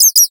bat_idle1.ogg